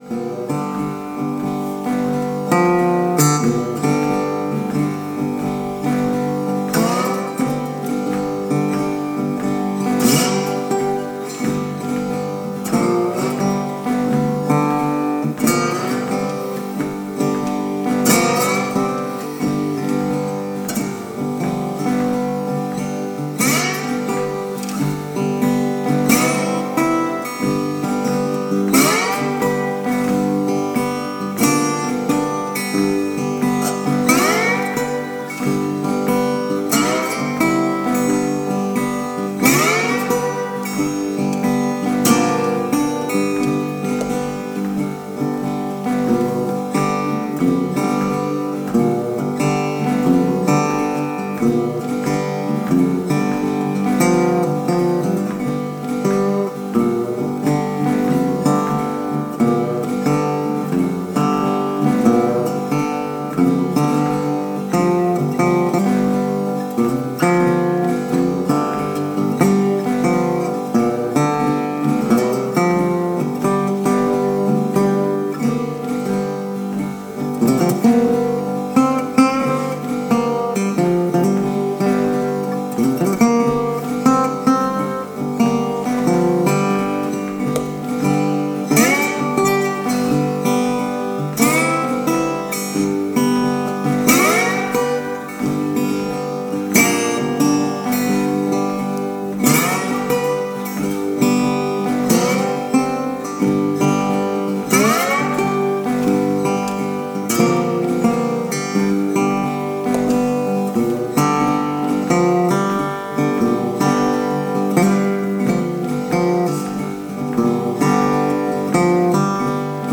Blues Gitarren Lofi.